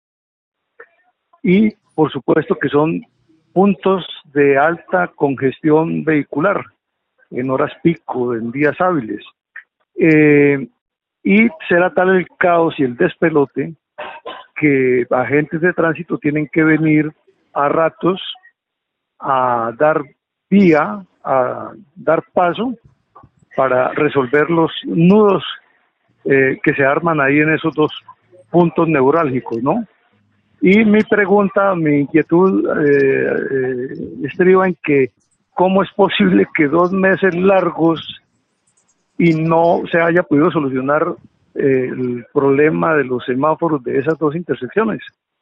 habitante de Bucaramanga